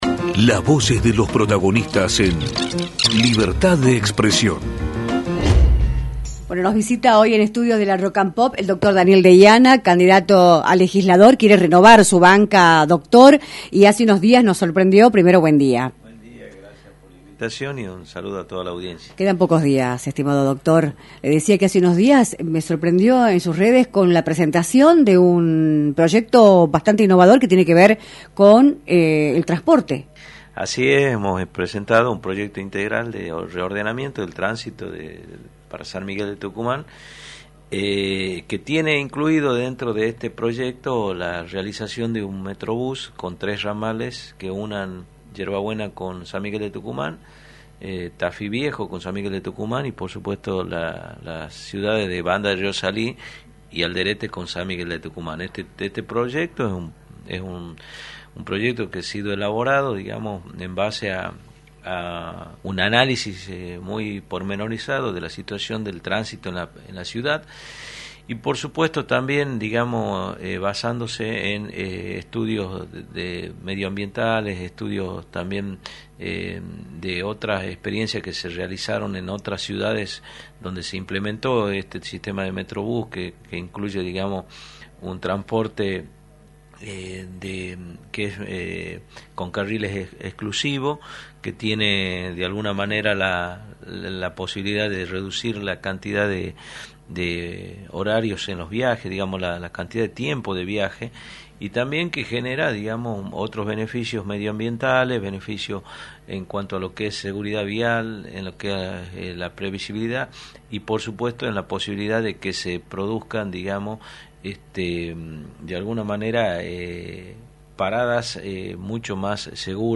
El Dr. Daniel Deiana, Legislador del oficialismo que buscará su reelección el próximo 14 de mayo, visitó los estudios de “Libertad de Expresión”, por la 106.9, para analizar el escenario político-electoral de la provincia y para informar a la ciudadanía sus propuestas, a diez días de los comicios.